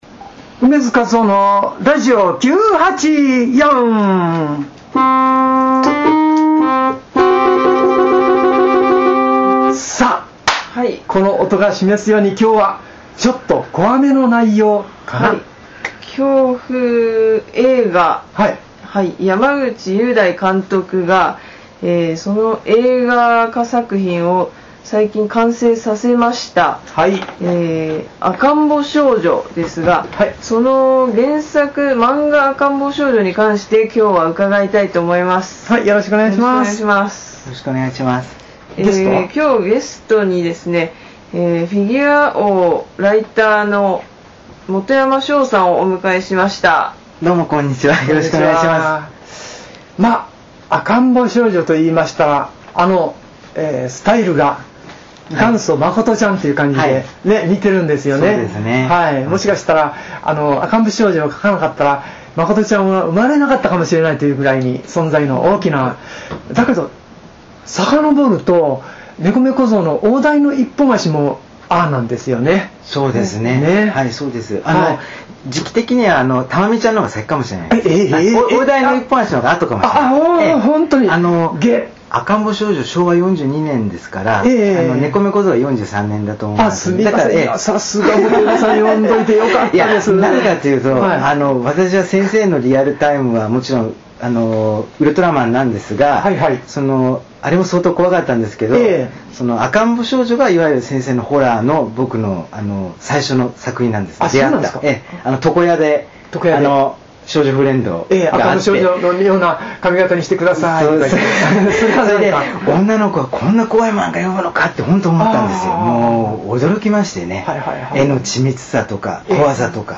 映画『赤んぼ少女』(山口雄大監督 公開時期未定)の完成を記念し、漫画『赤んぼ少女』について、楳図かずおが語ります！！楳図かずおがキーボードで奏でる恐怖の旋律も必聴どすえー！！